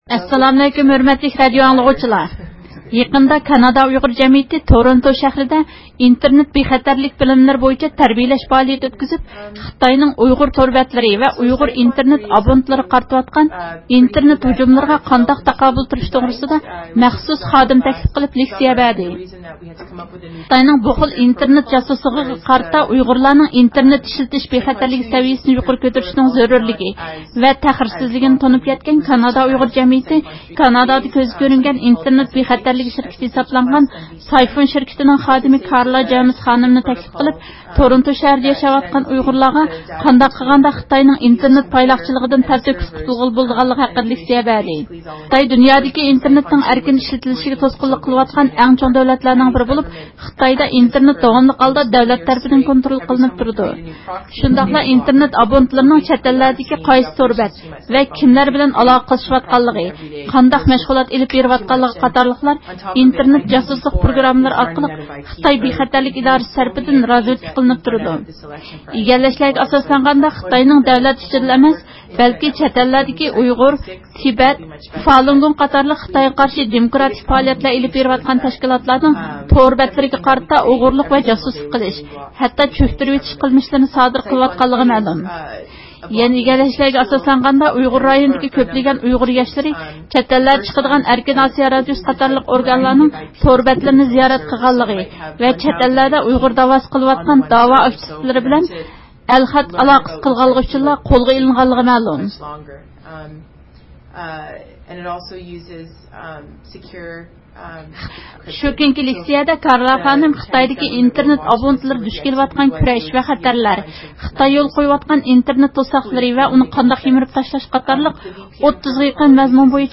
ئىختىيارى مۇخبىرىمىز